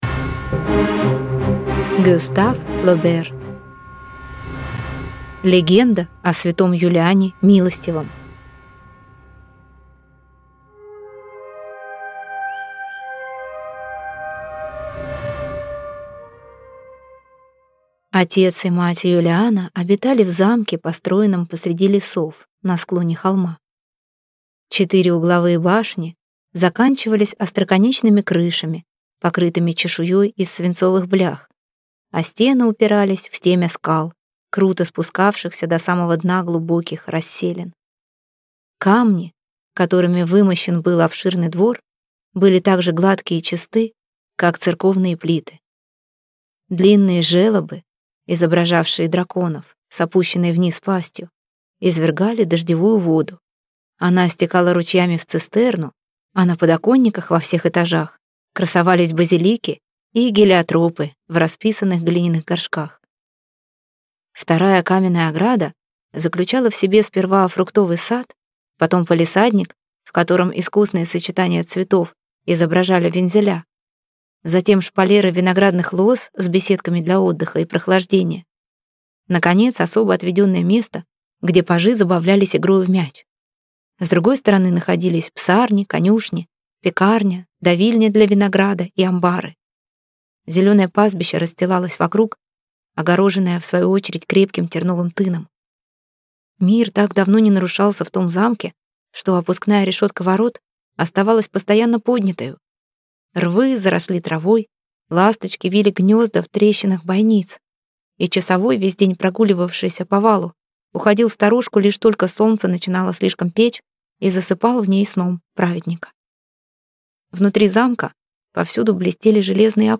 Аудиокнига Легенда о св. Юлиане Милостивом | Библиотека аудиокниг